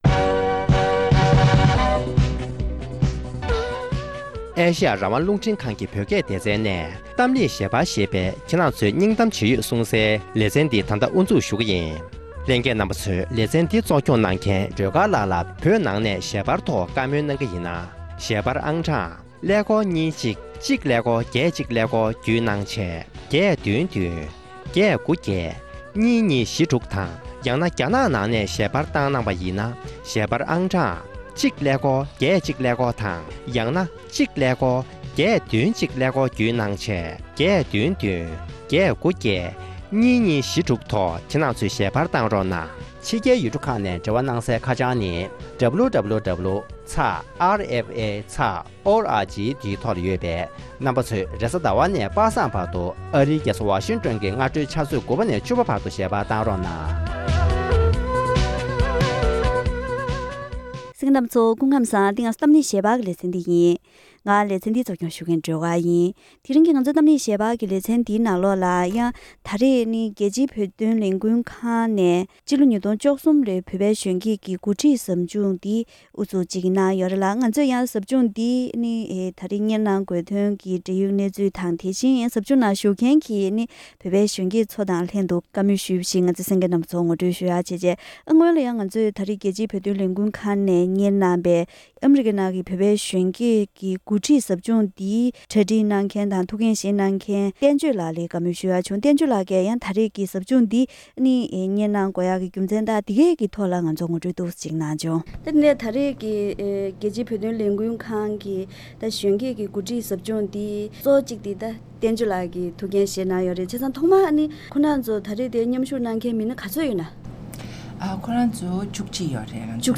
༄༅། །དེ་རིང་གི་གཏམ་གླེང་ཞལ་པར་ལེ་ཚན་ནང་།